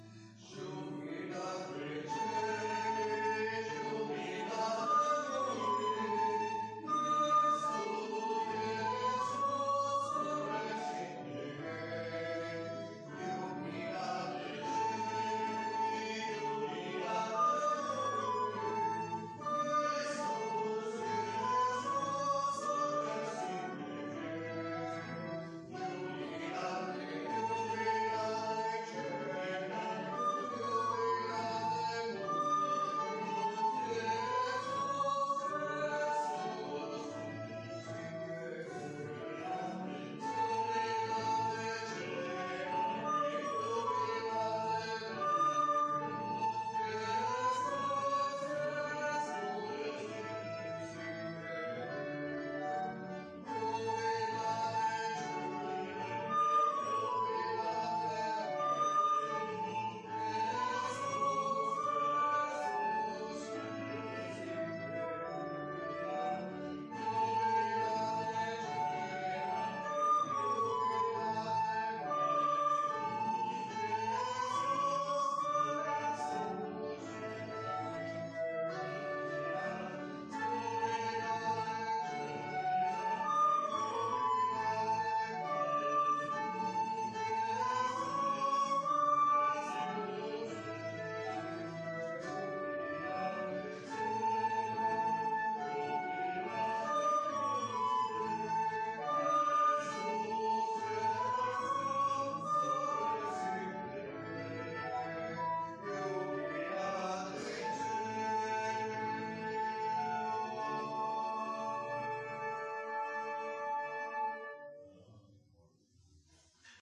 Pregària de Taizé a Mataró... des de febrer de 2001
Ermita de Sant Simó - Diumenge 26 de gener de 2020
també vàrem cantar...